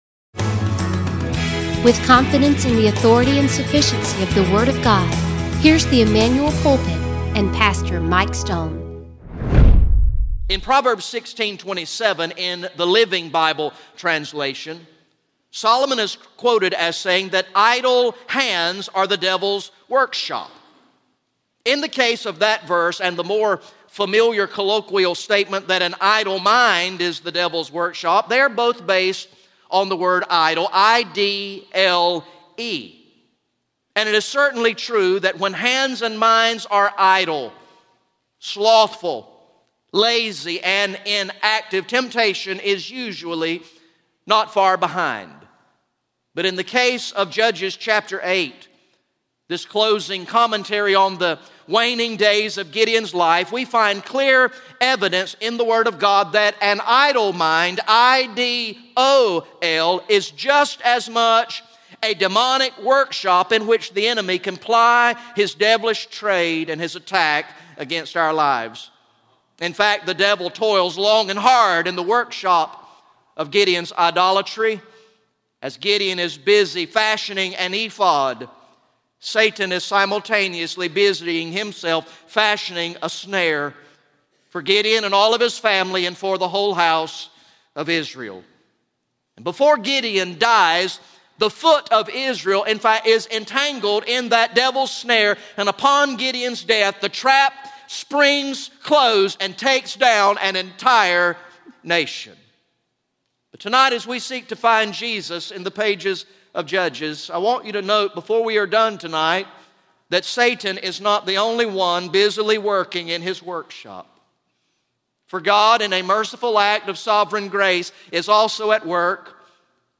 GA Message #18 from the sermon series through the book of Judges entitled "The Coming King